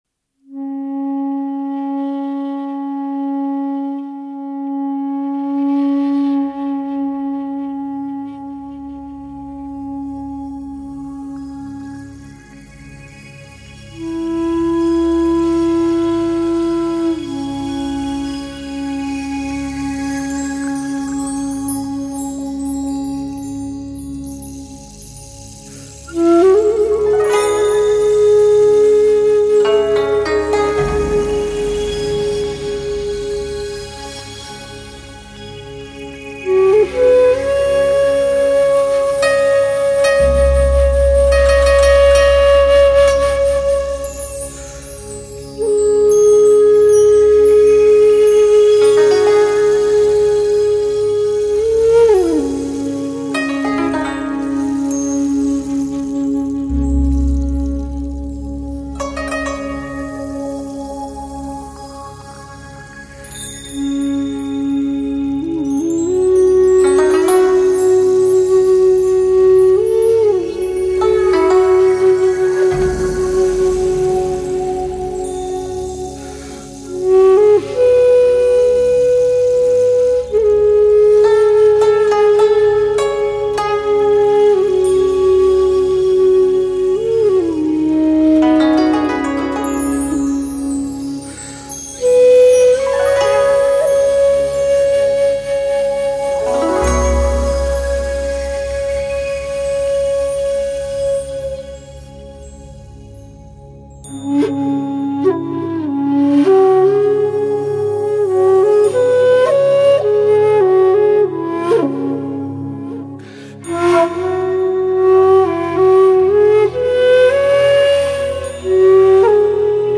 东方禅意音乐
古琴
古筝
女声
大提琴
这是一段虚无缥缈的音乐。